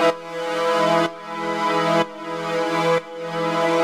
Index of /musicradar/sidechained-samples/125bpm
GnS_Pad-alesis1:2_125-E.wav